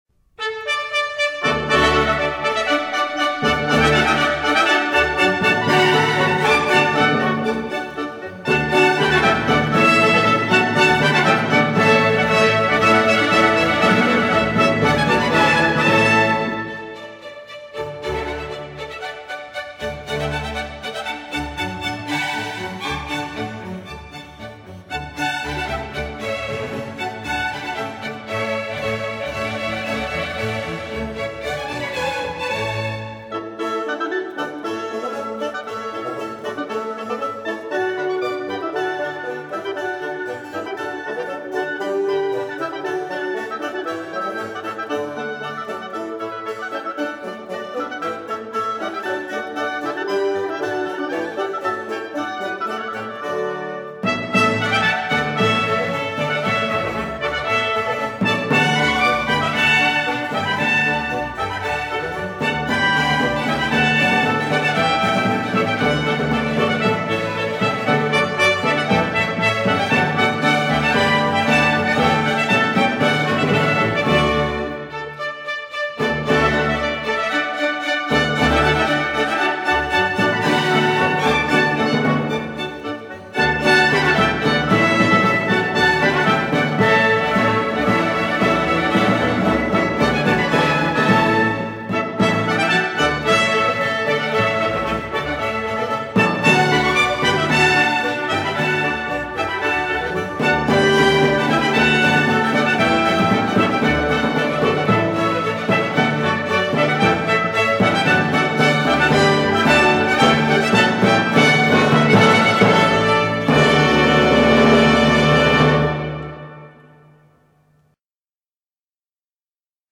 速度为快板，采用二部曲式。主题是嘹亮的巴洛克小号音调，活泼明快，情绪饱满而振奋。